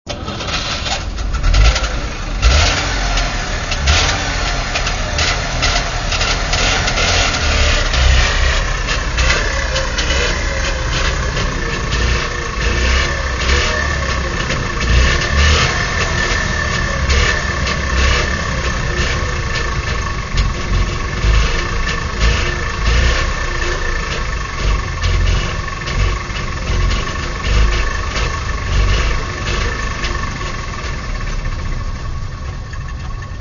Trabant 601 Motorenger�usch
Trabant601.mp3